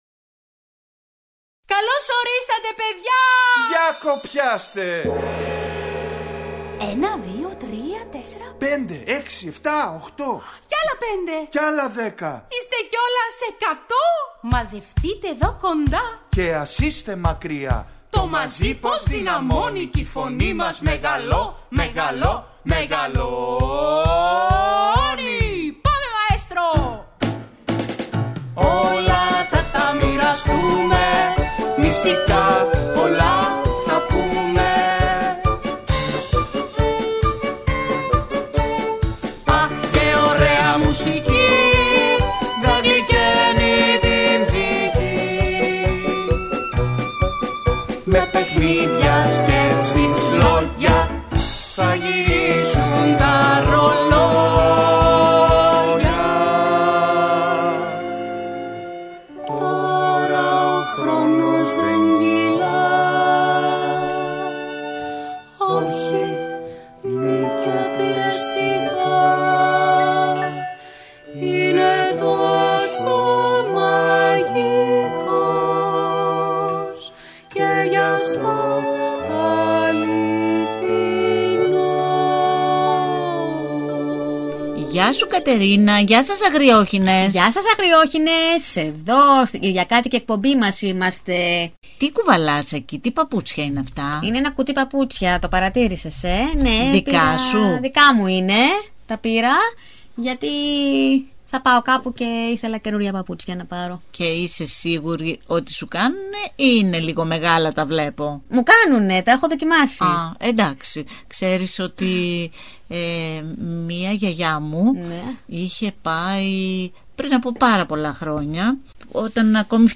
Ακούστε στην παιδική εκπομπή ‘’Οι Αγριόχηνες’’ το παραμύθι “Ο Άρης ο τσαγκάρης” του Ευγένιου Τριβιζά.